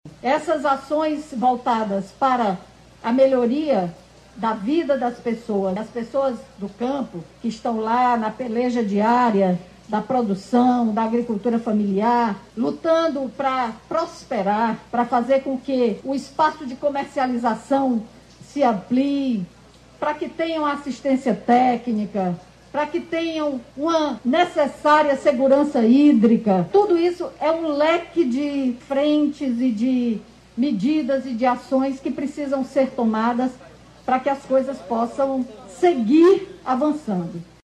A governadora Izolda Cela detalhou como a universalização possibilita segurança jurídica e cidadania a mulheres e homens cearenses que residem e trabalham no campo.